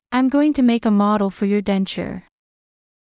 日本語でも「モデル」といいますが、英語の発音は少し違います。
ｱｲﾑ ｺﾞｰｲﾝｸﾞ ﾄｩ ﾒｲｸ ｱ ﾓｰﾀﾞﾙ ﾌｫｰ ﾕｱ ﾃﾞﾝﾁｬｰ